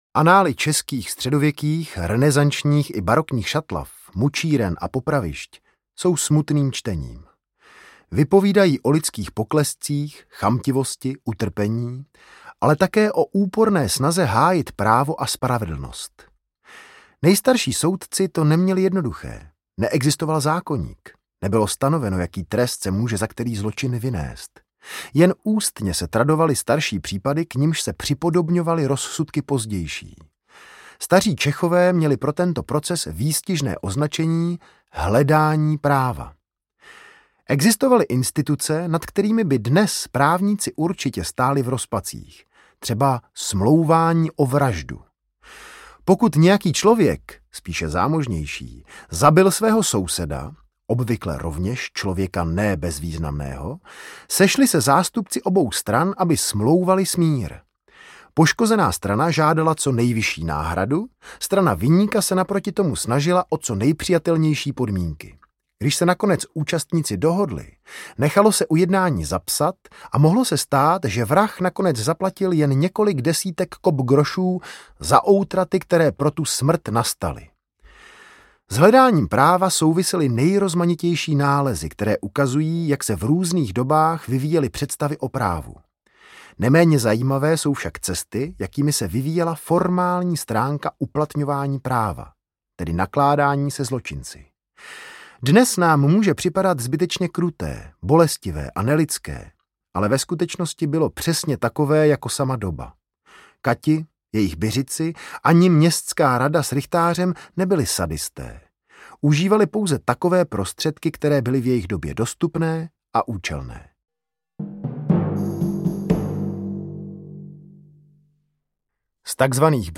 Katovny a mučírny audiokniha
Ukázka z knihy
Vyrobilo studio Soundguru.